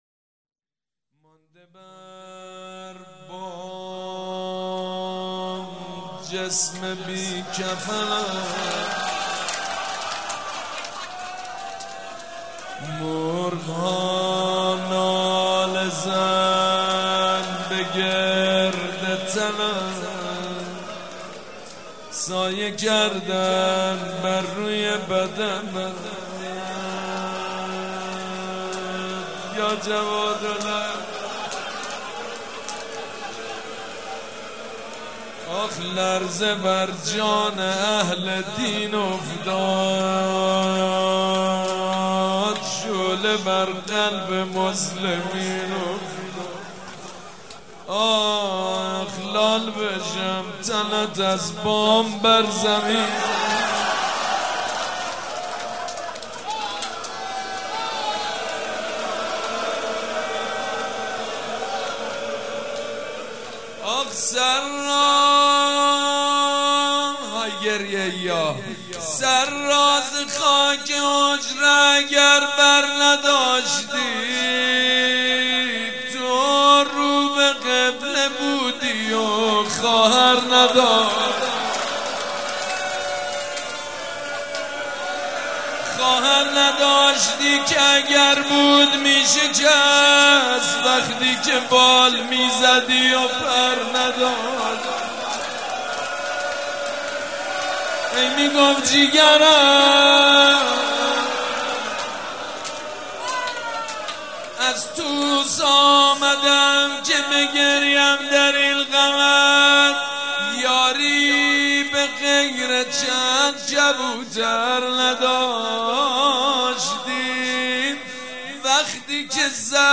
روضه
شهادت امام جواد (ع)